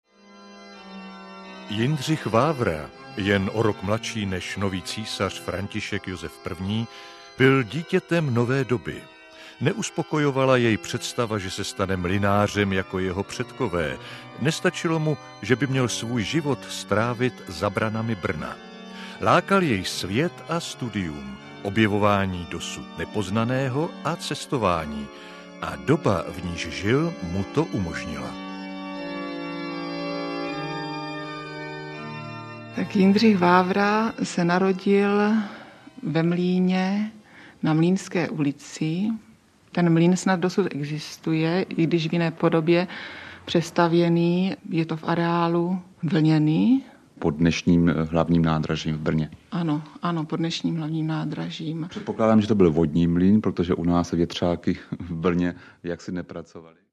Vyberte Audiokniha 289 Kč Další informace